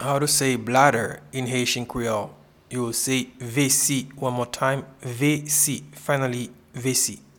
Pronunciation and Transcript:
Bladder-in-Haitian-Creole-Vesi.mp3